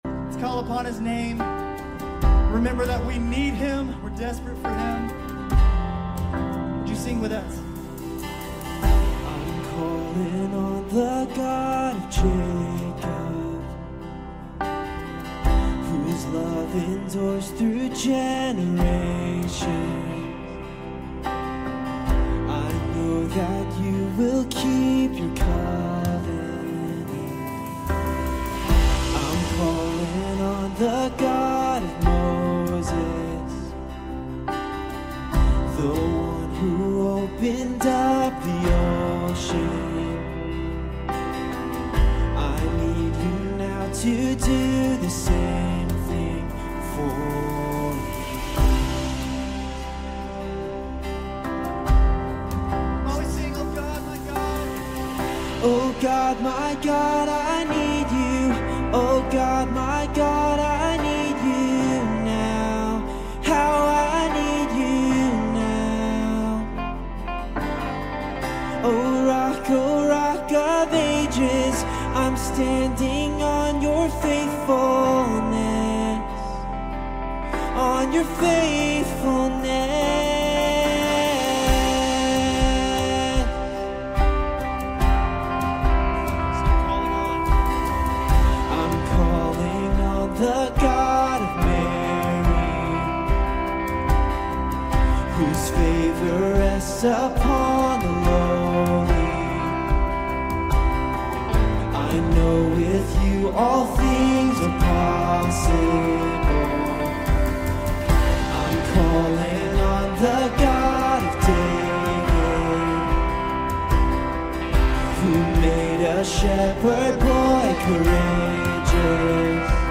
Grace Community Church University Blvd Campus Sermons 11_9 University Blvd Campus Nov 10 2025 | 01:13:21 1x 00:00 / 01:13:21 Subscribe Share RSS Feed Share Link Embed